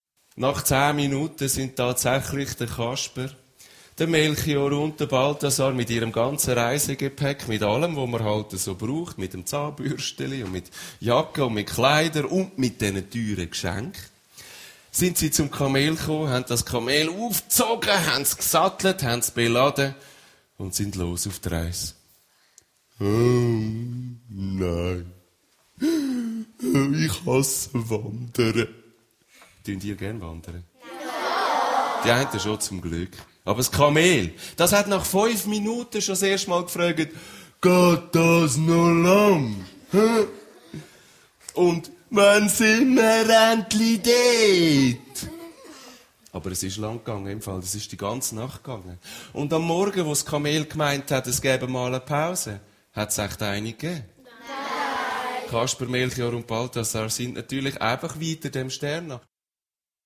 Ravensburger Du Kamel ✔ tiptoi® Hörbuch ab 4 Jahren ✔ Jetzt online herunterladen!